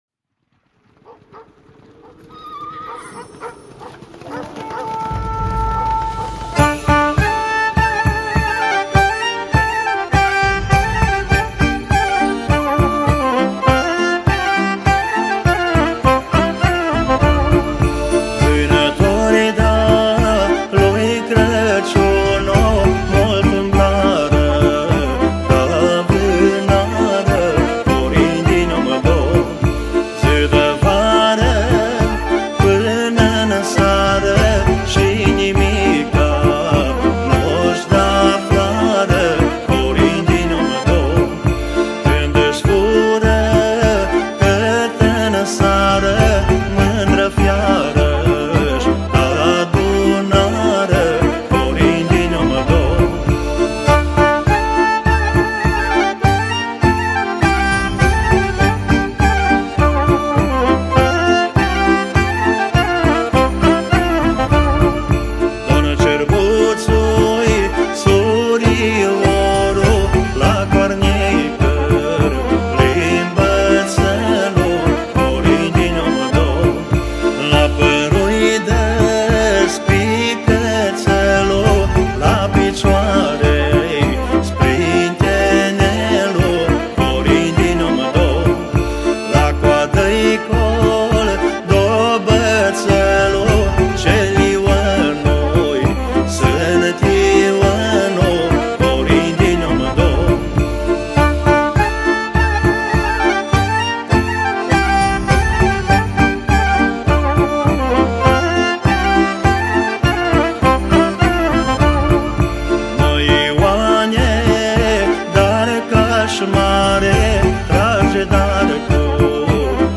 Data: 02.10.2024  Colinde Craciun Hits: 0